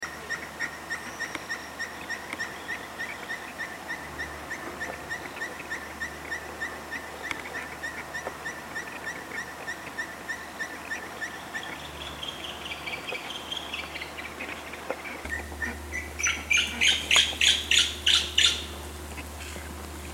Caburé Grande (Glaucidium nana)
Nombre en inglés: Austral Pygmy Owl
Localidad o área protegida: Reserva Natural Villavicencio
Condición: Silvestre
Certeza: Fotografiada, Vocalización Grabada
cabure-villa-baja.mp3